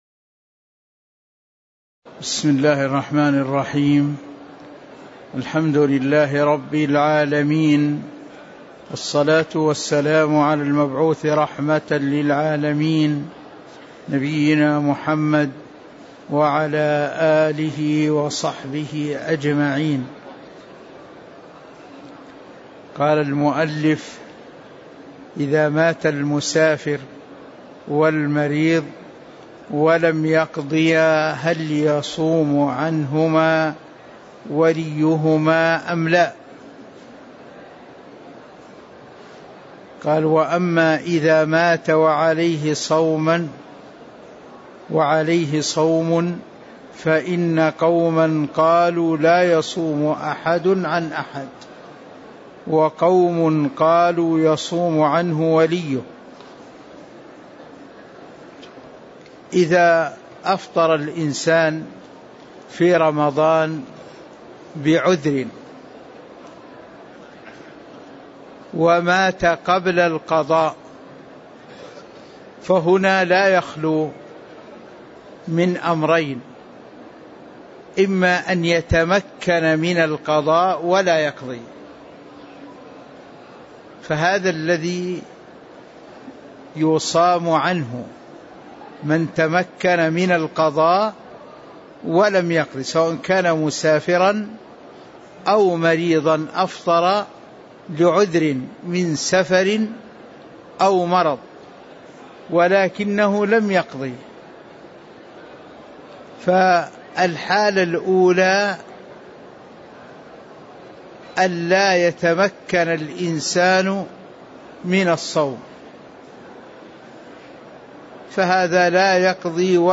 تاريخ النشر ٧ جمادى الآخرة ١٤٤٦ هـ المكان: المسجد النبوي الشيخ